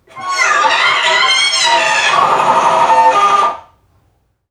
NPC_Creatures_Vocalisations_Robothead [79].wav